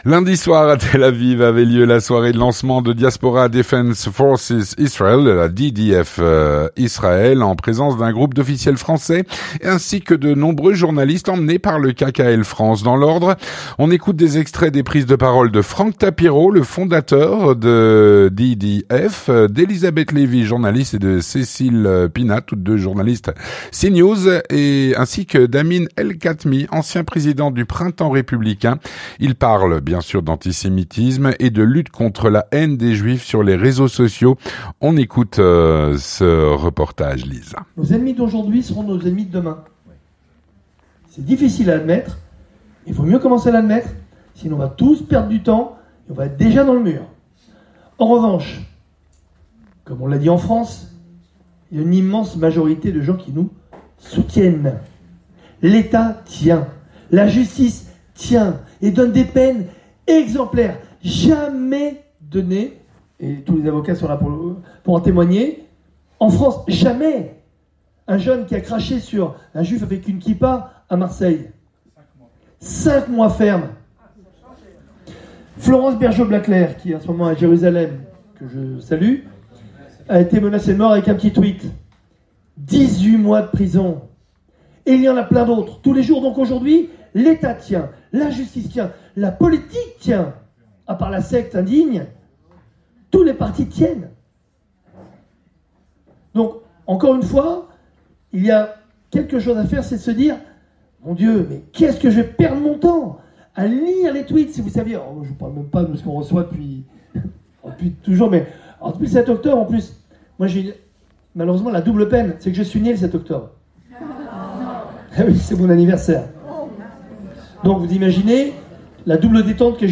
Lundi soir à Tel Aviv avait lieu la soirée de lancement de Diaspora Defense Forces Israël, en présence d’un groupe d’officiels français et de nombreux journalistes emmenés par le KKL France.
Un reportage